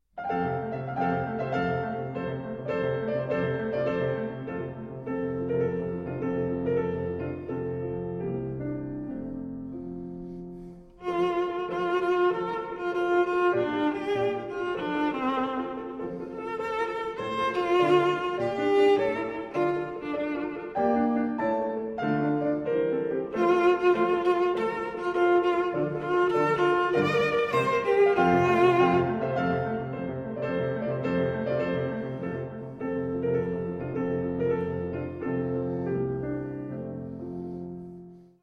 前奏部分に着目してお聴きください。
ヴィオラ
ピアノ